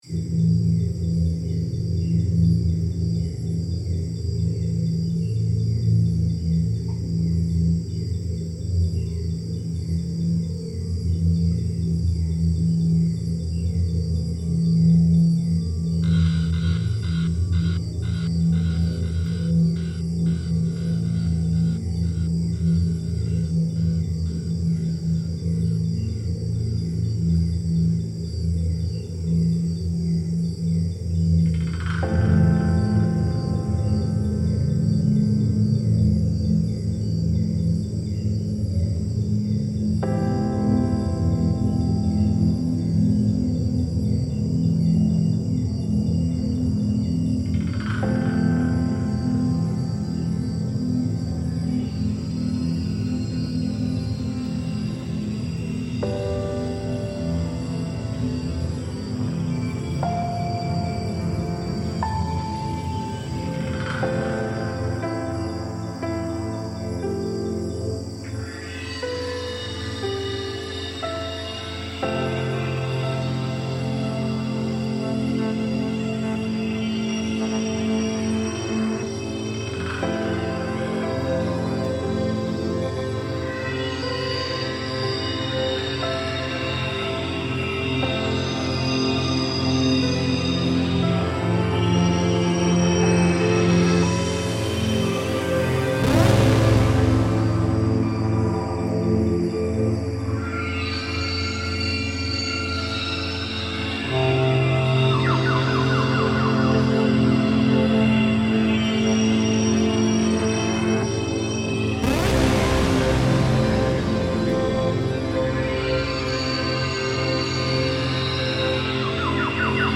Borneo rainforest reimagined